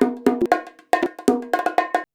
119BONG15.wav